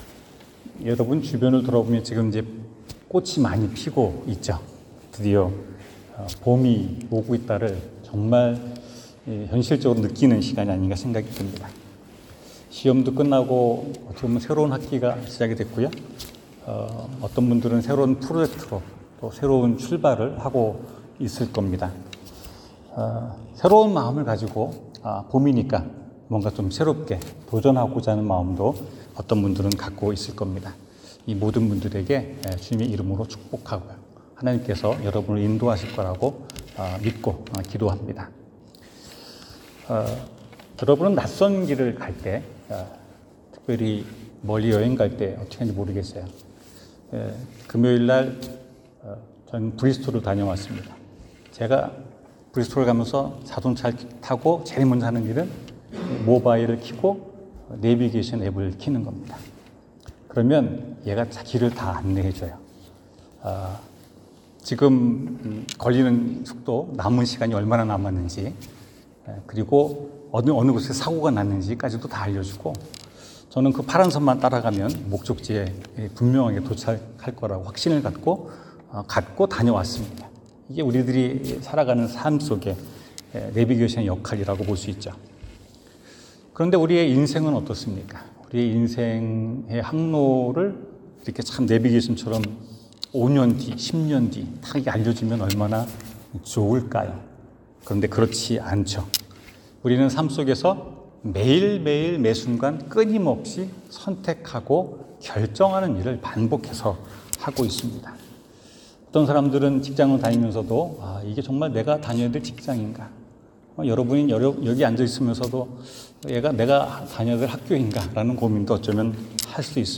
안전지대를 떠나는 용기 성경: 창세기 12:1-4 설교